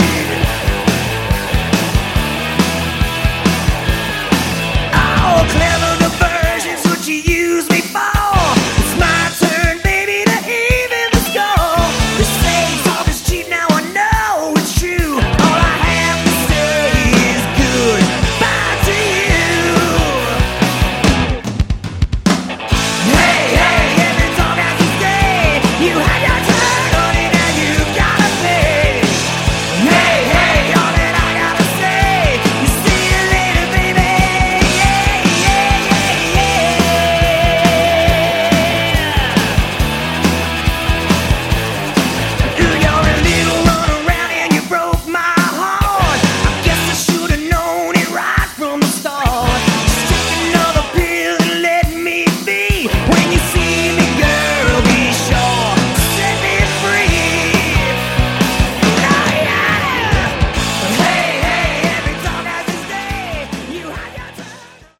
Category: Sleaze Glam
lead vocals, harmonica
guitar, vocals
lead guitar, vocals
bass, vocals
drums, percussion